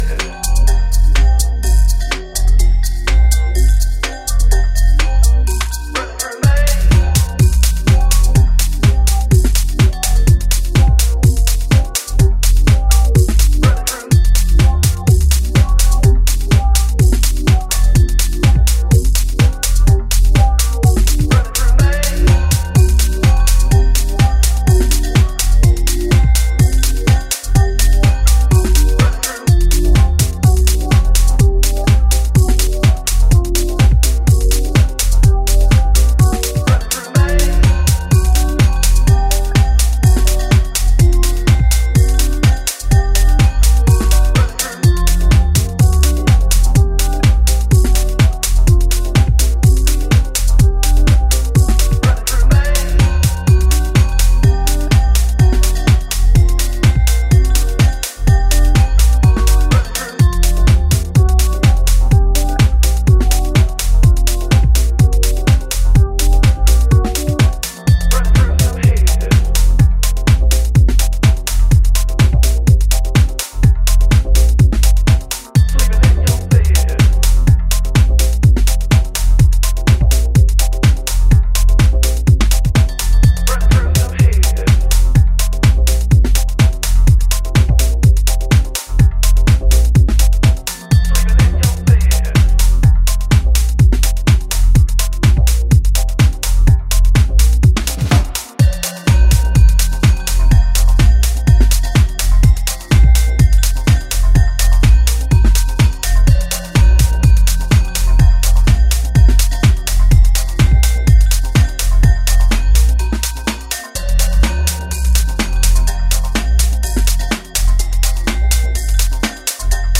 detail and timeless club music.